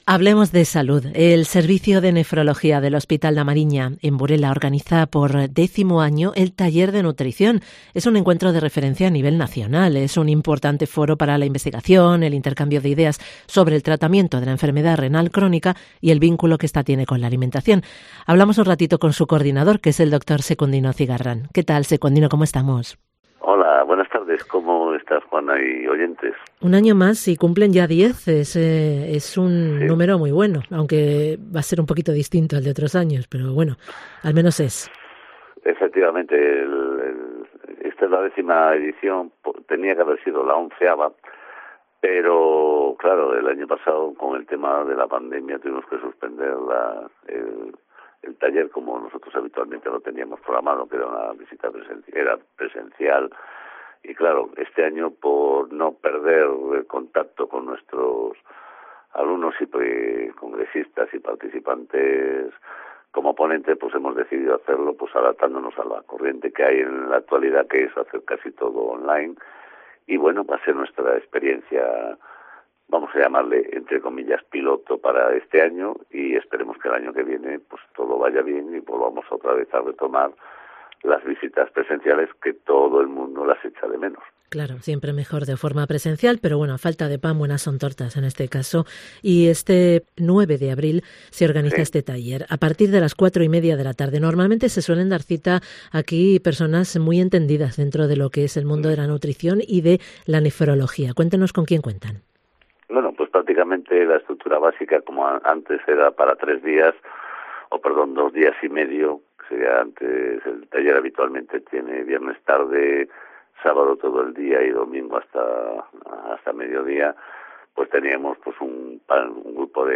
AUDIO: Entrevista con el coordinador del Taller de Nutriciión